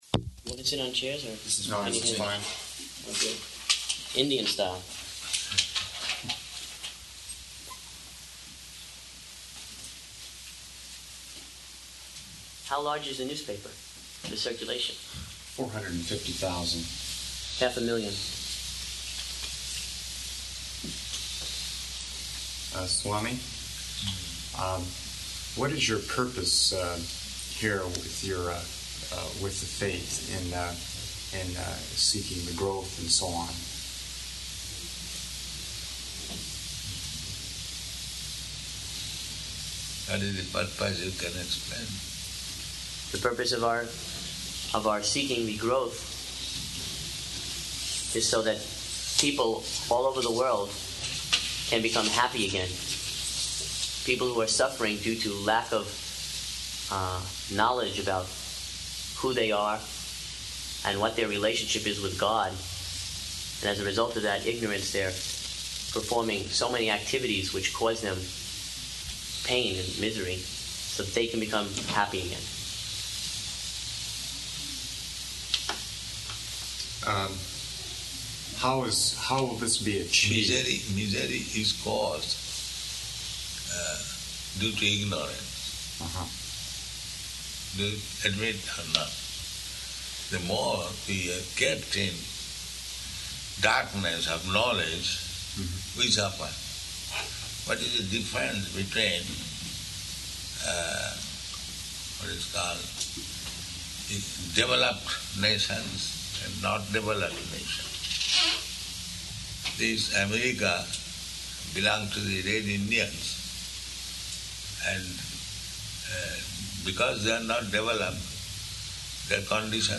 Room Conversation with Reporter